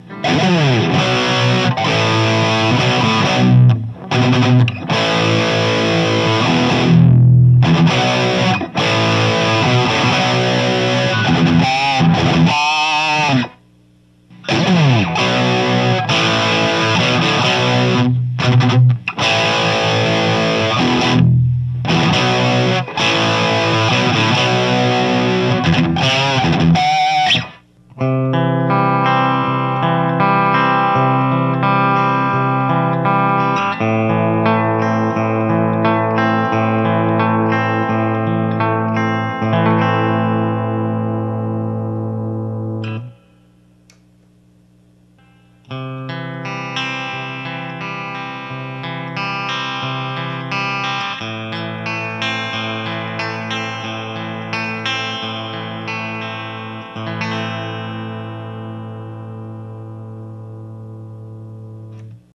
ディープはオンだったと思います。
歪みとクリーンです。
デュアルとシングルの歪みとクリーンです。
JCM 2000 DSL100
GAIN7 Bass8 Middle10 Treble7
マイク　SHURE　SM57